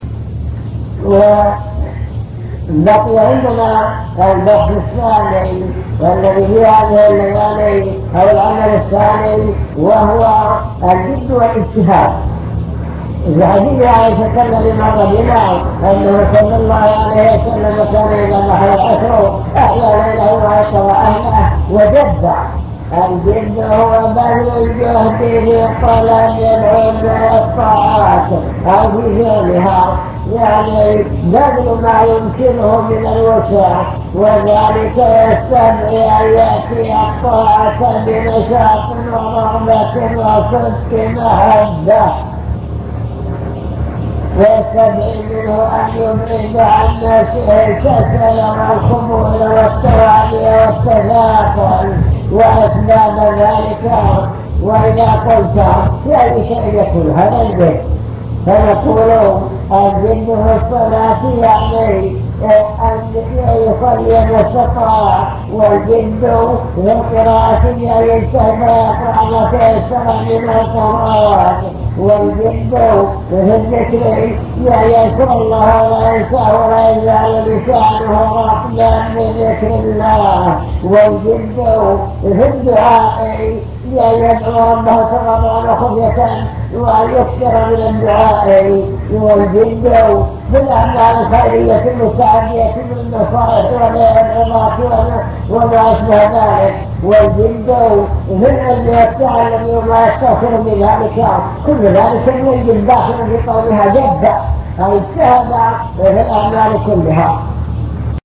المكتبة الصوتية  تسجيلات - محاضرات ودروس  مجموعة محاضرات ودروس عن رمضان العشر الأواخر من رمضان